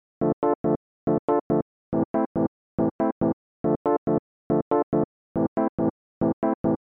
雷盖风琴
描述：适合我的雷鬼钢琴和雷鬼鼓
Tag: 70 bpm Reggae Loops Organ Loops 1.15 MB wav Key : Unknown